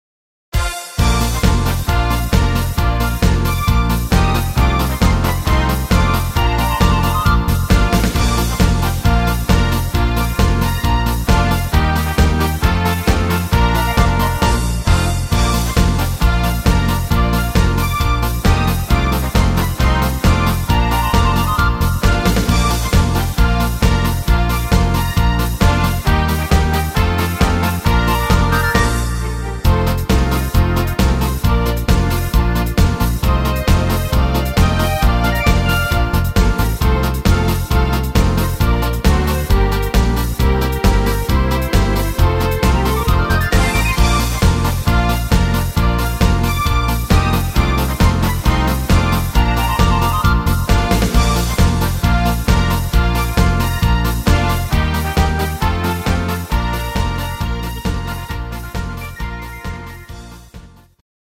Playback abmischen  Playbacks selbst abmischen!
Rhythmus  8 Beat
Art  Deutsch, Medleys, Schlager 90er